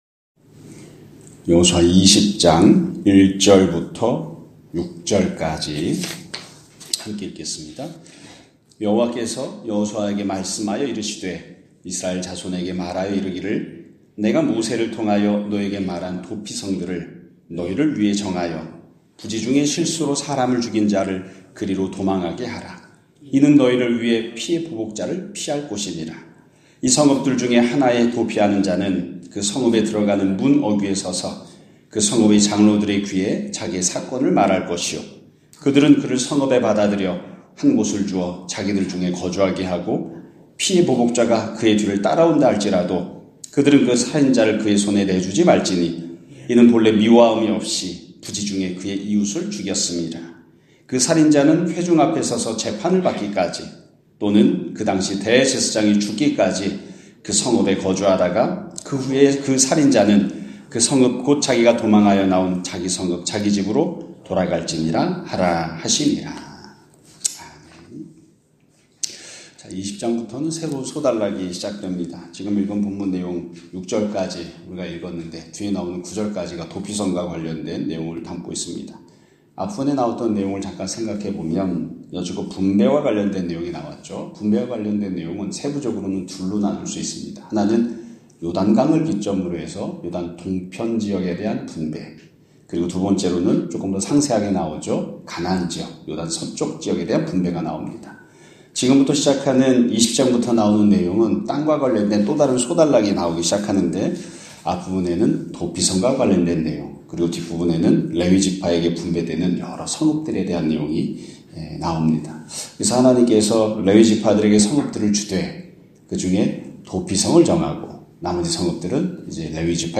2025년 1월 15일(수요일) <아침예배> 설교입니다.